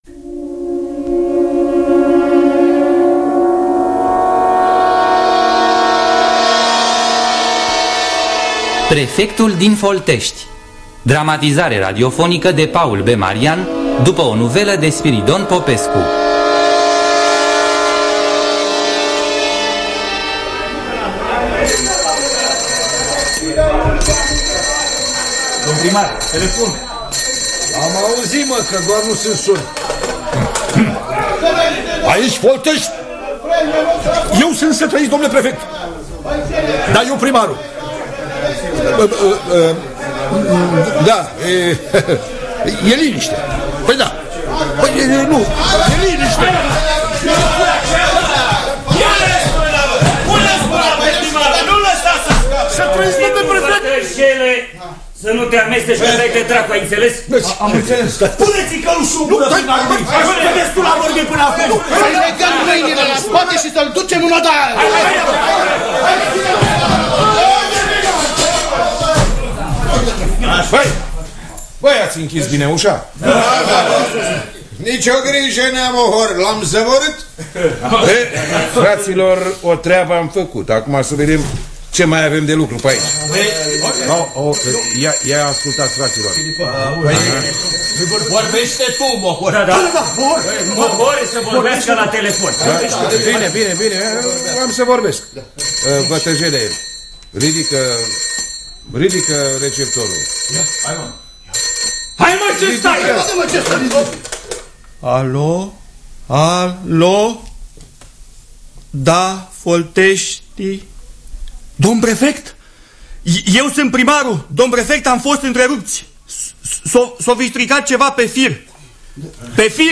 Dramatizarea radiofonică de Paul B. Marian.